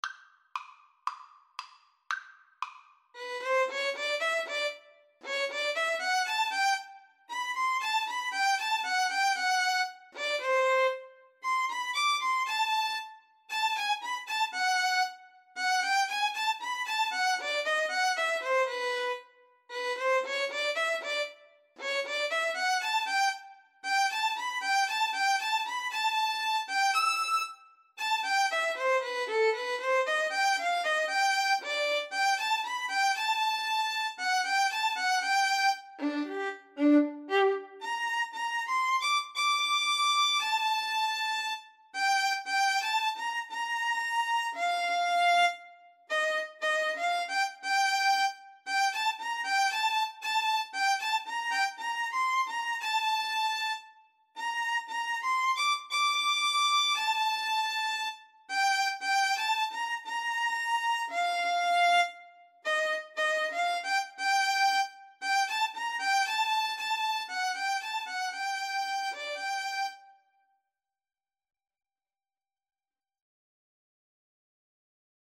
tango song
2/4 (View more 2/4 Music)